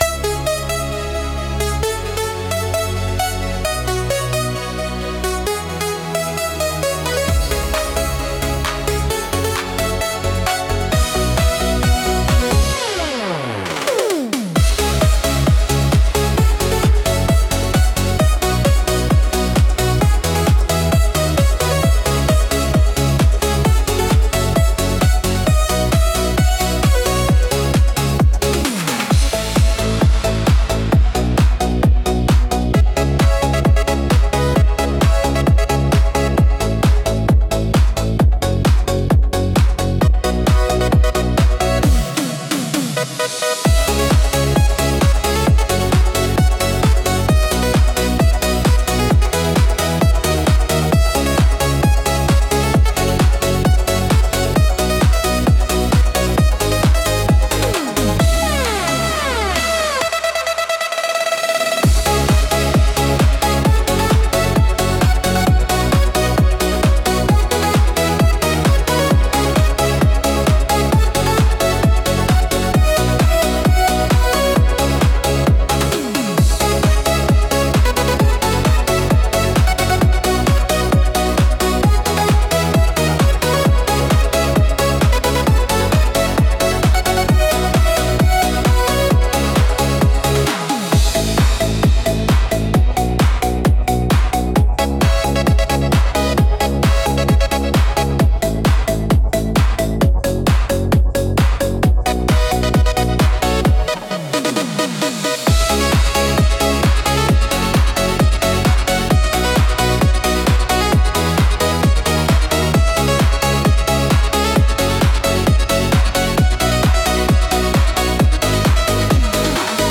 Instrumental - Futurepop Romance 2.39